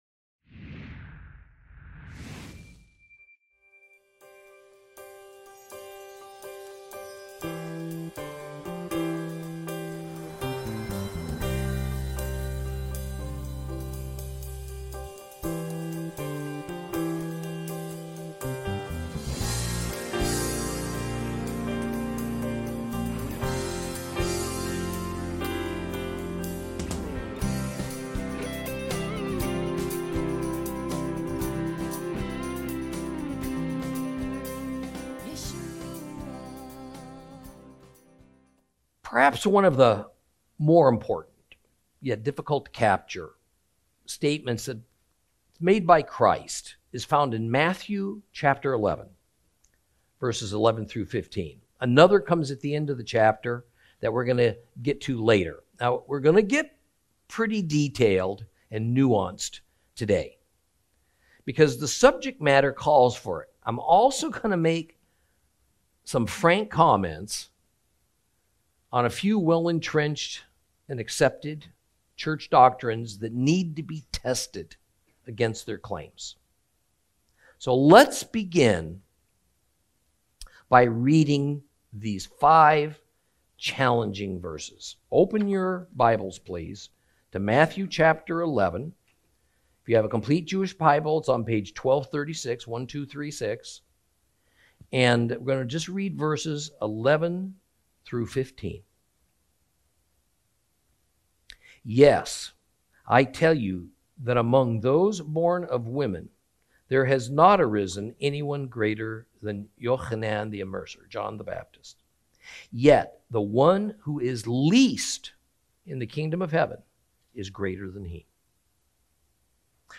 Lesson 40 Ch11 - Torah Class